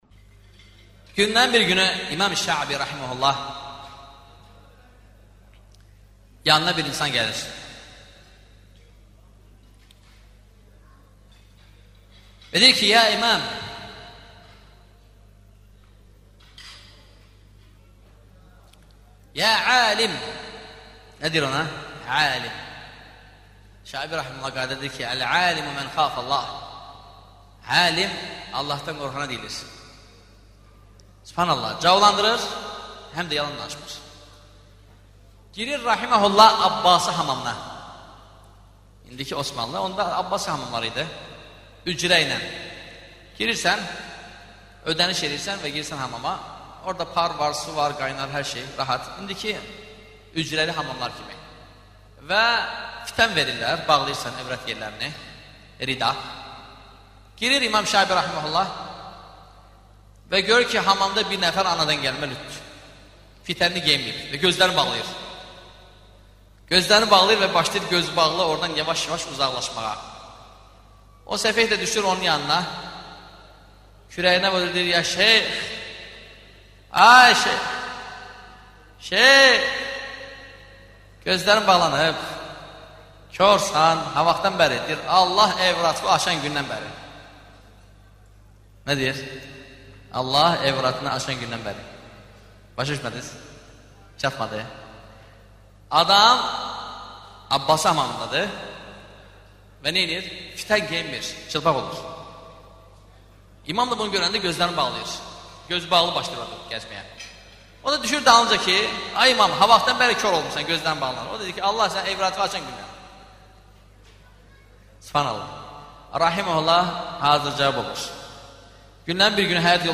Dərslərdən alıntılar – 49 parça